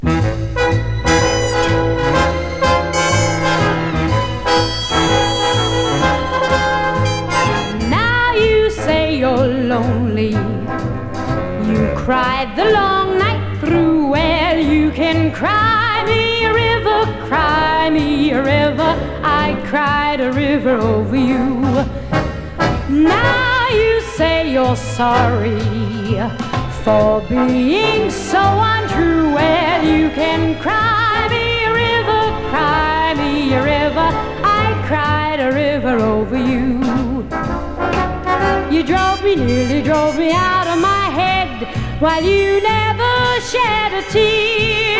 スタンダード楽曲を、伸びやかな歌声がとても良いです。個性としての表現の良さをじっくり感じられるバラード曲も素敵です。
Pop, Vocal, Jazz　USA　12inchレコード　33rpm　Mono